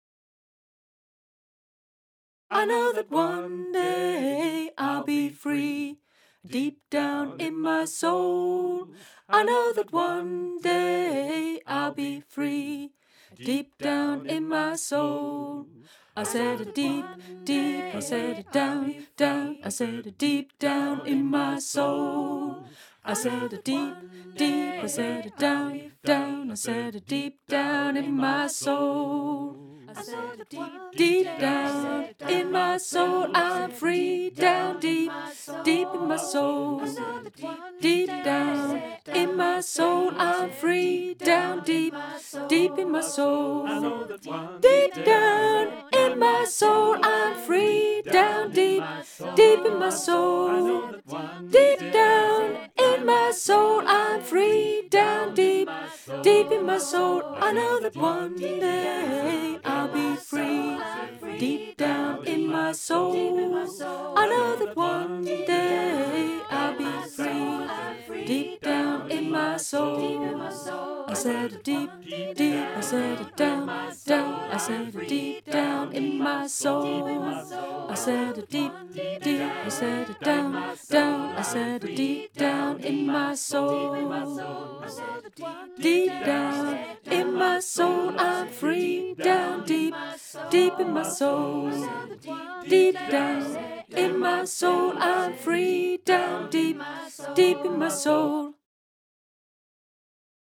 11 Deep Down in my Soul (Melody learning track)
Genre: Choral.